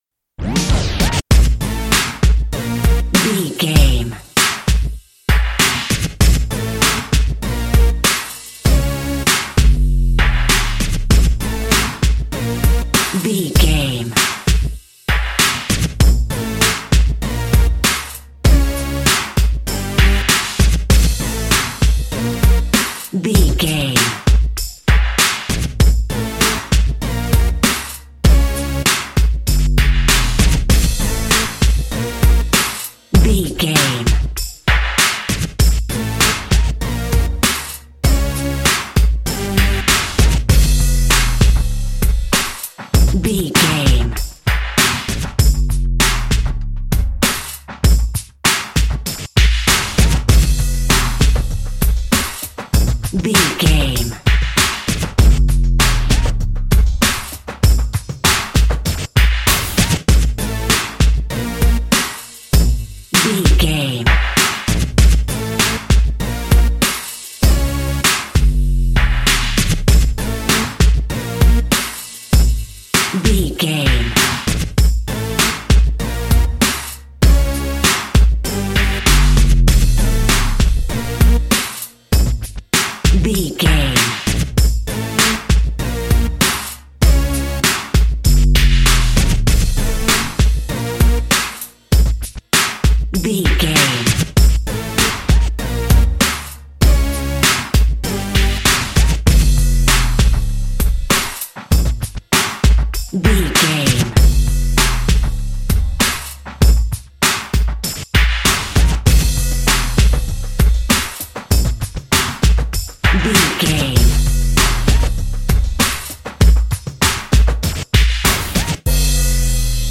Ionian/Major
drum machine
synthesiser
funky